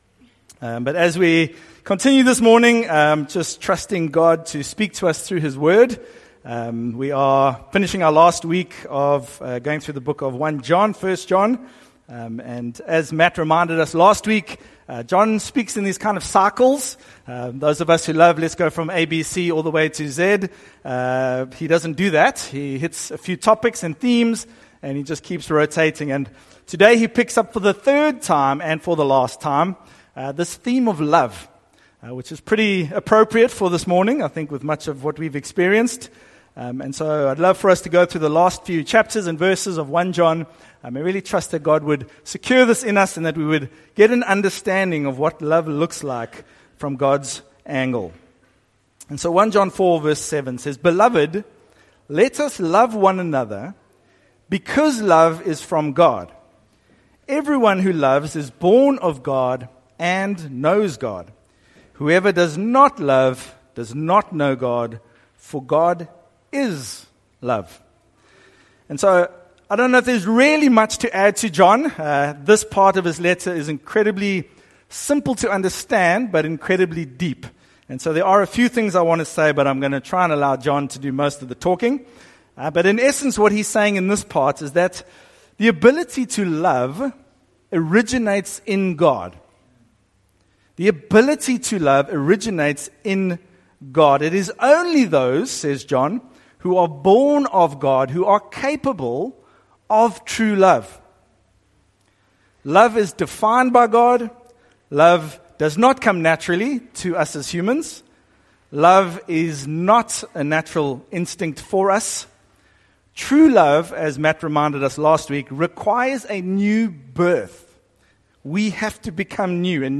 SERMON: Eternal Life – Love in Christ